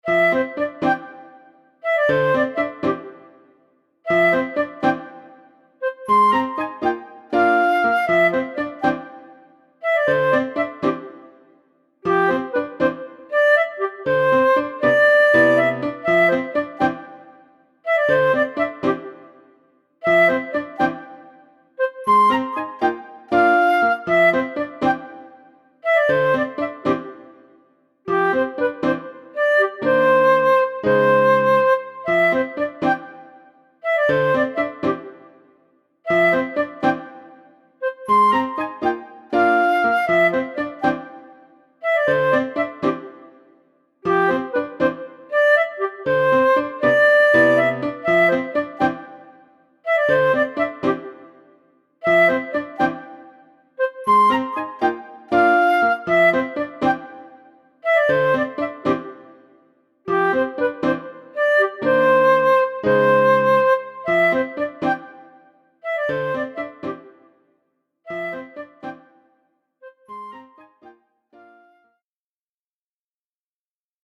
ピアノとフルートのシンプルで明るい曲です